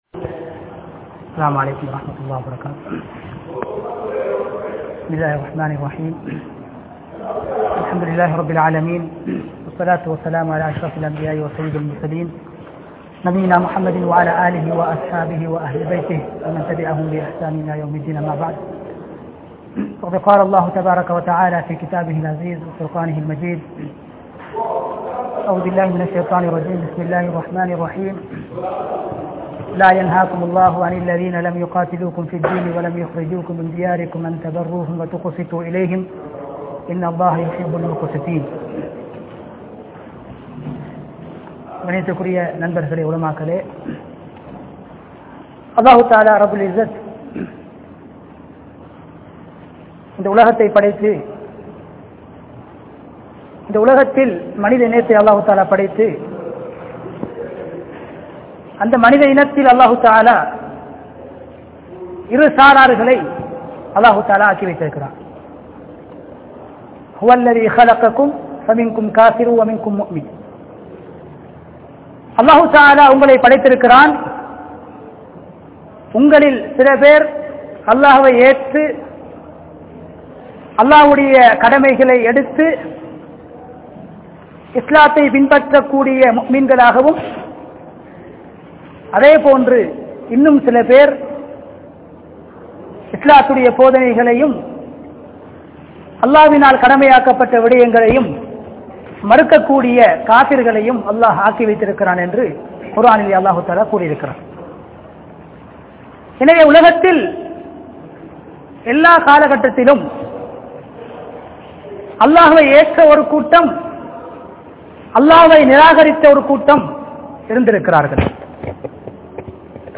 Islam Koorum Saha Vaalvu (இஸ்லாம் கூறும் சகவாழ்வு) | Audio Bayans | All Ceylon Muslim Youth Community | Addalaichenai